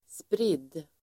Uttal: [sprid:]